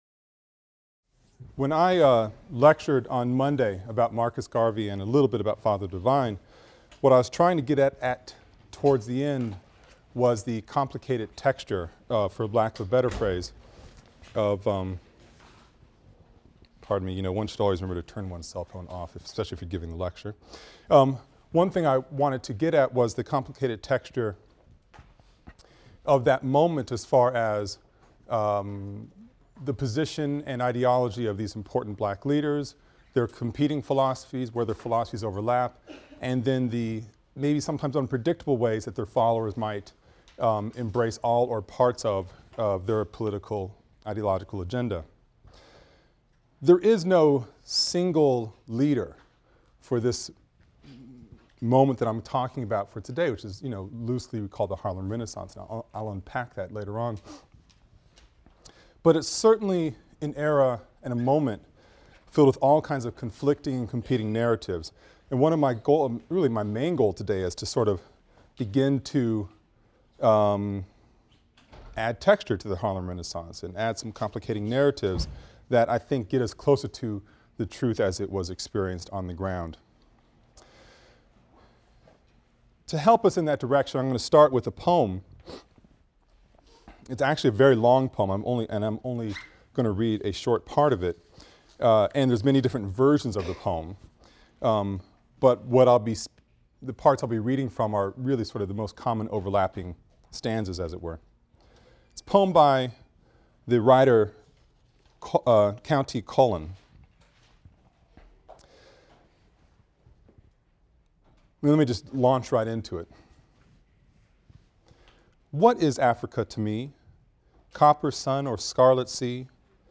AFAM 162 - Lecture 10 - The New Negroes (continued) | Open Yale Courses